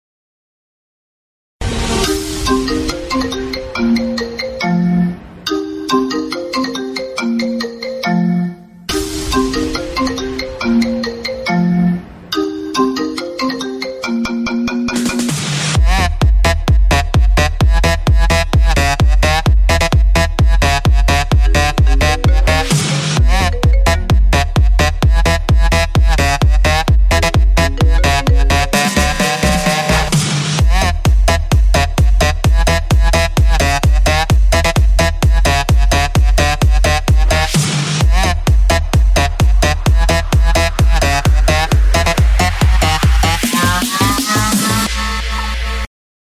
tải nhạc nền nhạc chuông iPhone Tik Tok remix mp3 hay nhất